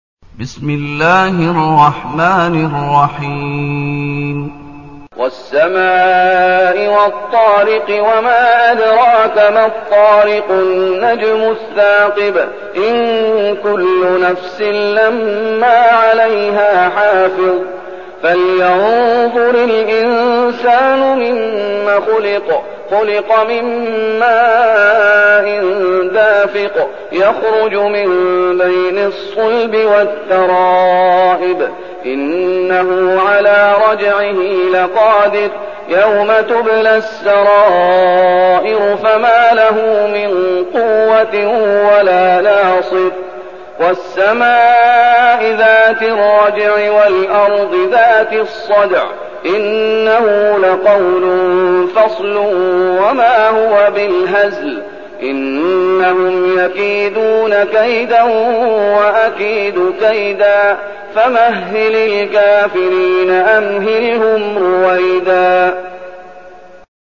المكان: المسجد النبوي الشيخ: فضيلة الشيخ محمد أيوب فضيلة الشيخ محمد أيوب الطارق